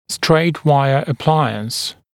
[streɪt ‘waɪə ə’plaɪəns][стрэйт ‘уайэ э’плайэнс]аппаратура прямой дуги